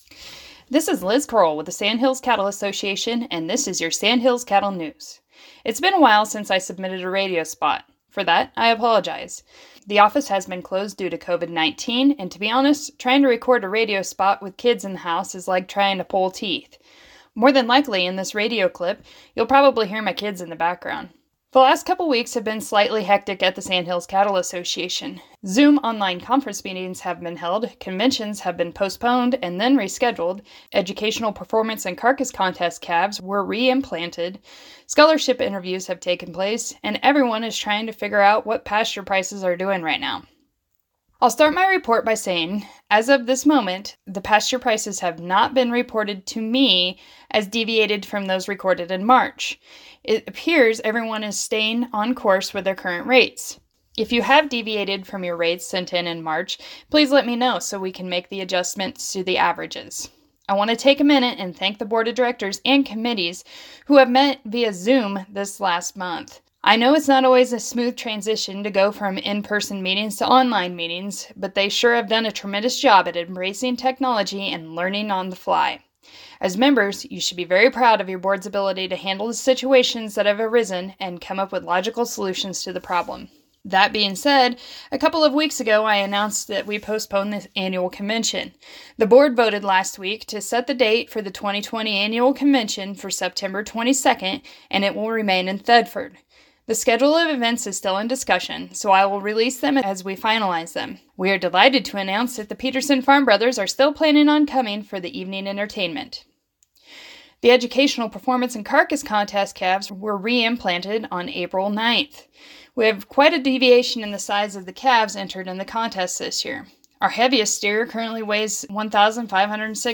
KVSH Radio Spot - Sandhills Cattle News -